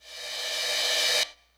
crash03.wav